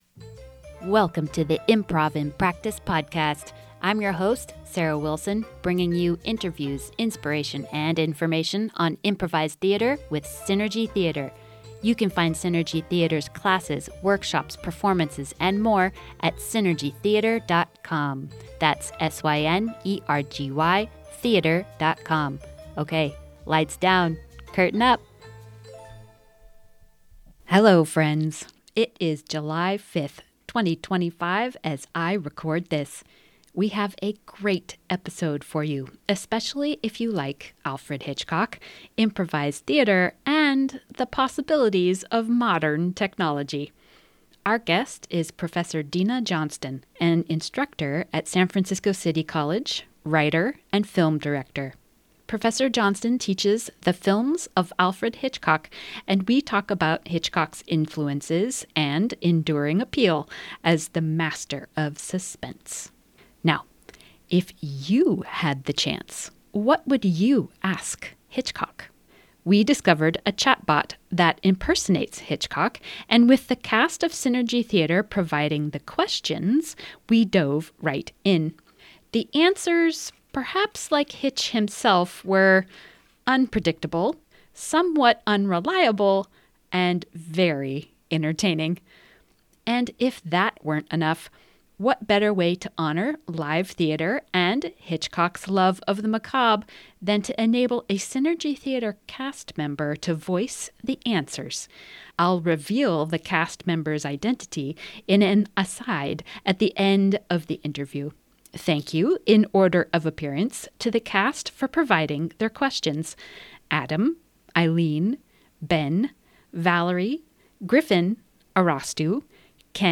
We also host a virtual Alfred Hitchcock plumbed from the depths of the ethereal world of Artificial Intelligence. If you could ask Alfred Hitchcok anything, would you take his answers as the whole truth?